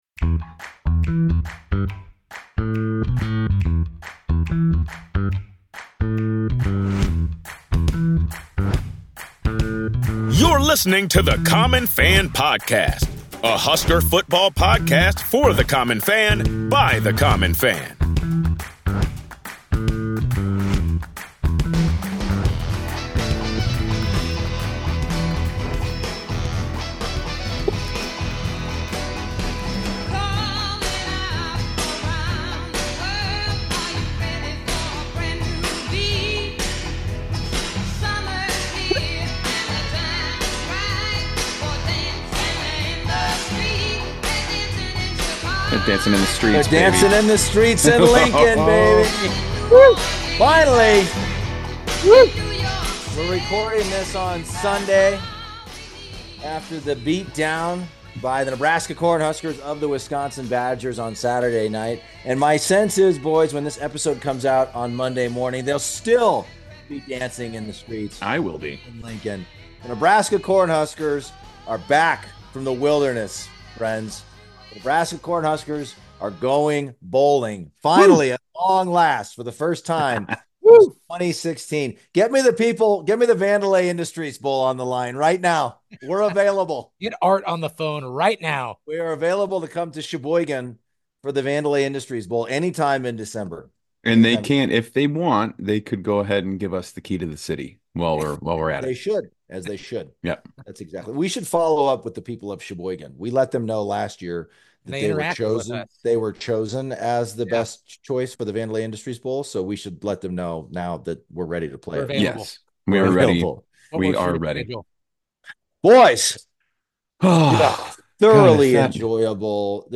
The Common Fans are jubilant as they discuss Nebraska’s first win over Wisconsin in 12 years.